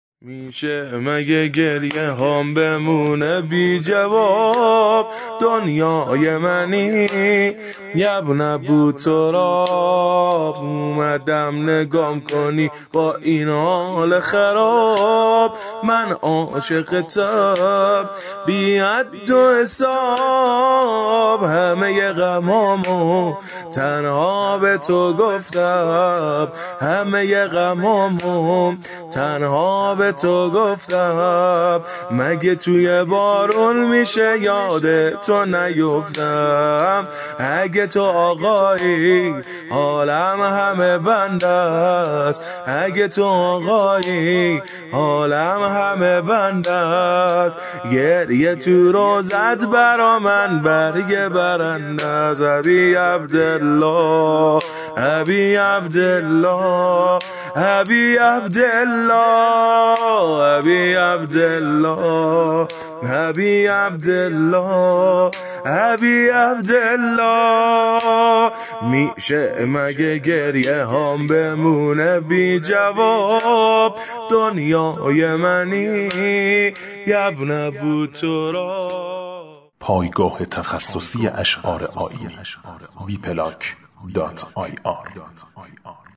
زینب کبری رحلت شور ، واحد